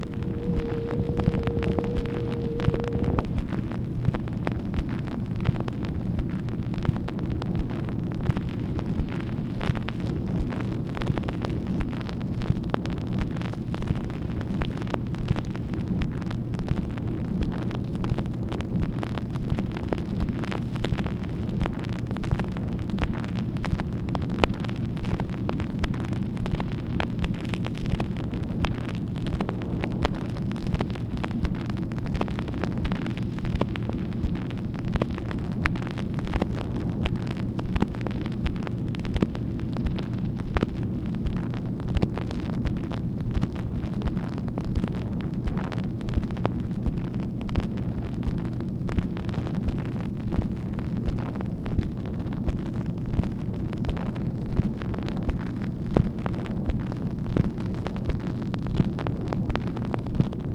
MACHINE NOISE, August 5, 1964
Secret White House Tapes | Lyndon B. Johnson Presidency